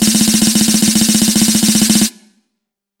Snare Roll
A tight snare drum roll building in intensity with crisp wire rattle and crescendo
snare-roll.mp3